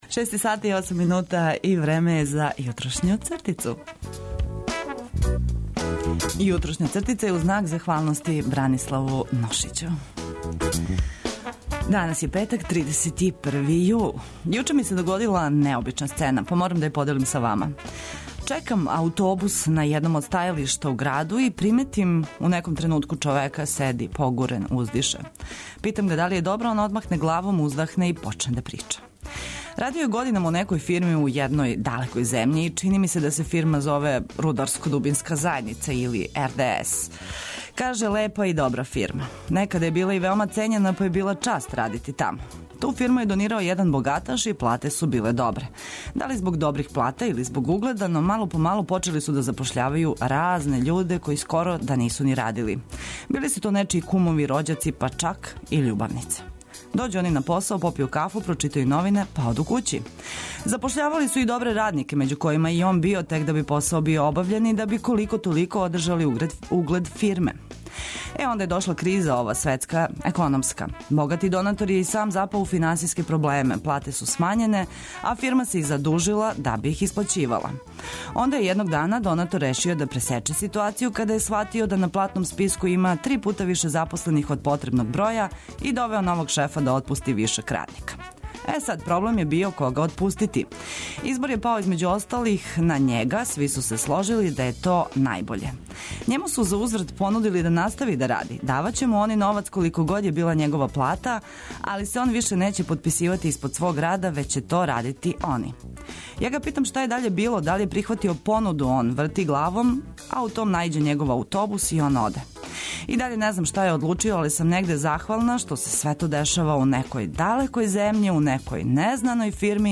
Зато се и овог јутра будимо уз позитивне мисли и одличну музику.